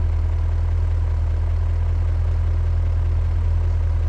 rr3-assets/files/.depot/audio/Vehicles/v8_11/v8_11_idle.wav
v8_11_idle.wav